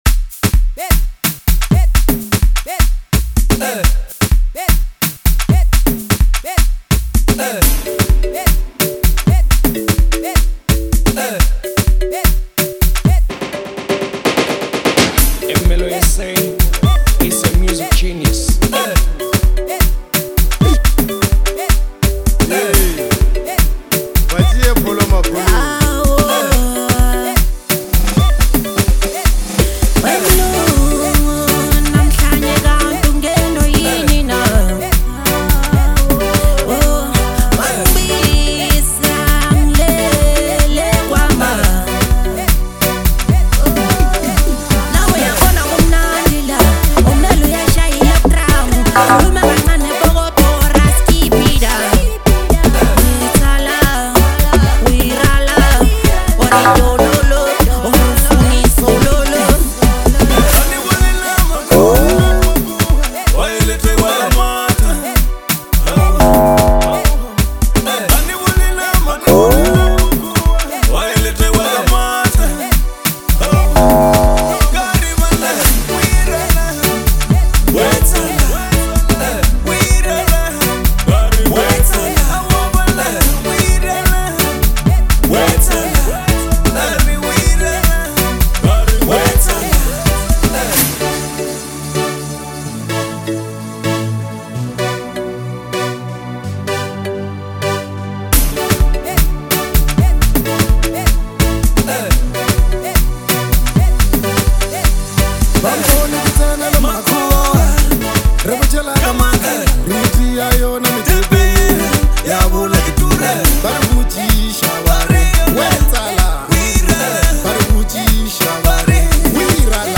an electrifying track